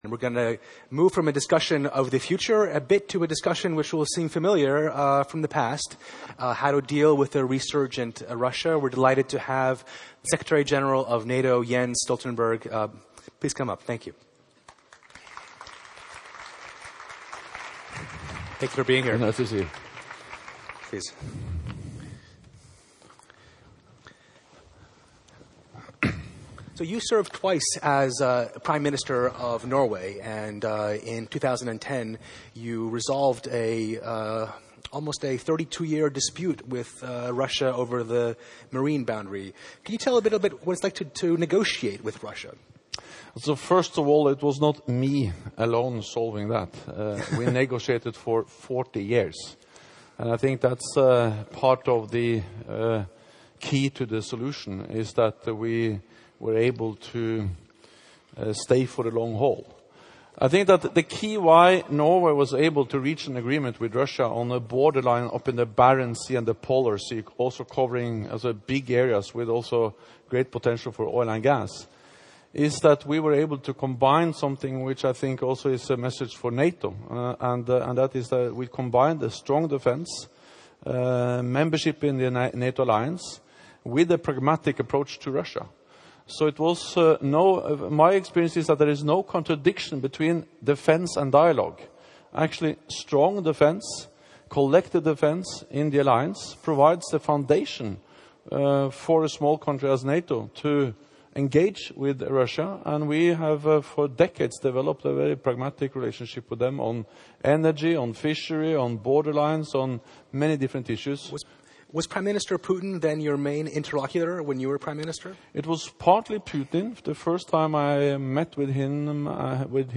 at the launch of Politico Europe